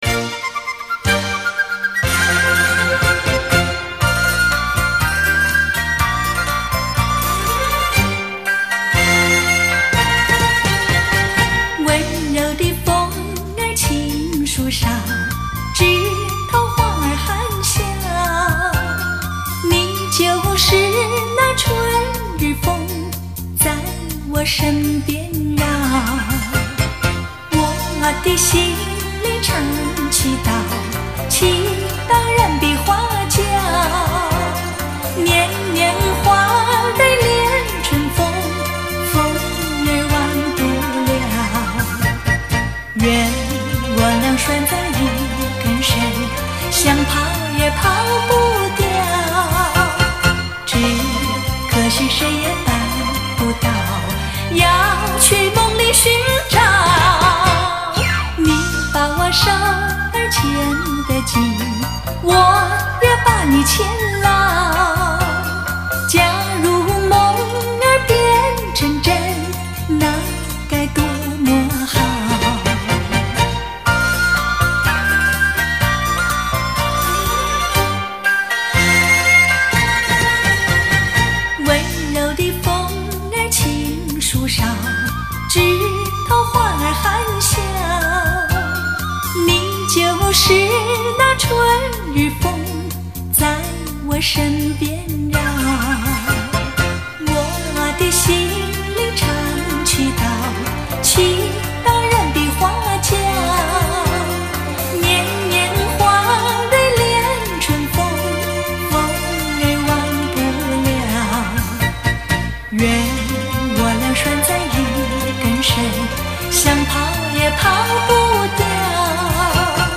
关键是录音极佳， 低电平，高密度